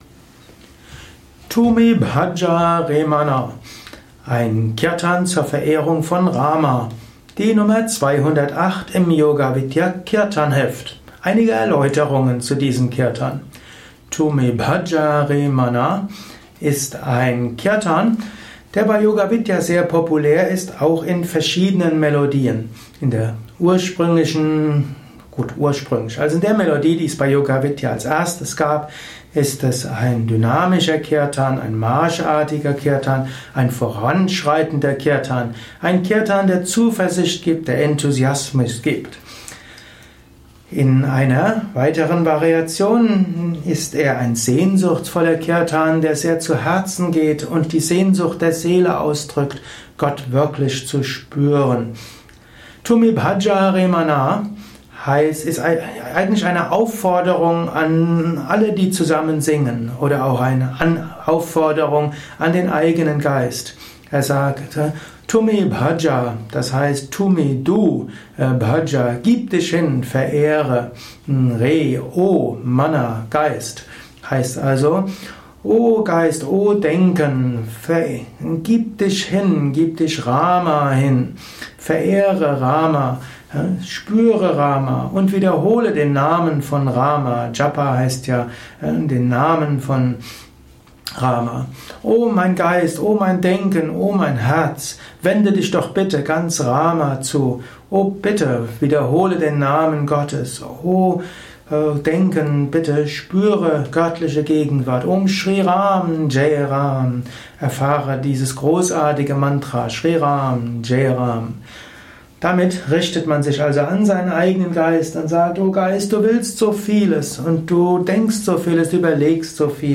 Audio mp3 Erläuterungen